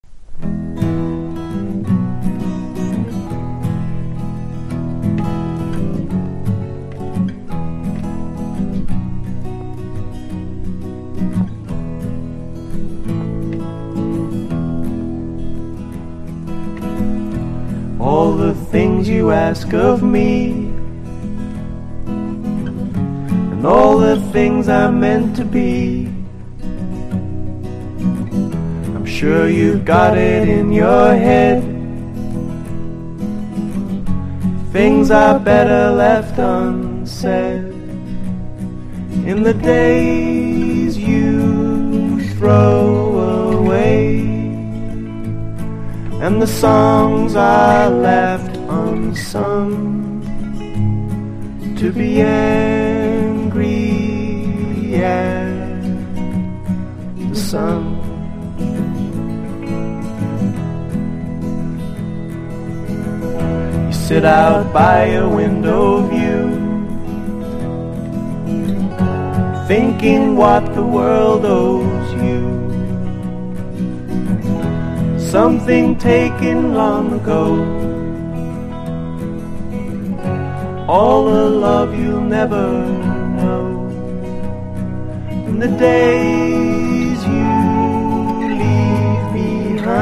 1. 00S ROCK >
NEO ACOUSTIC / GUITAR POP